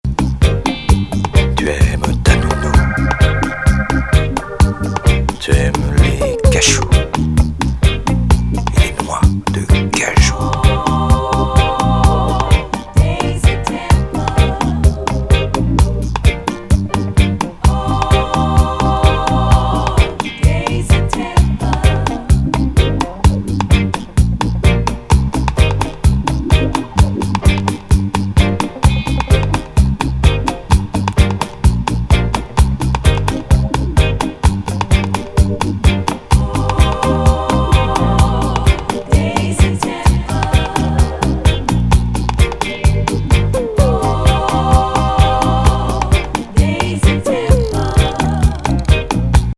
変レゲー12″!!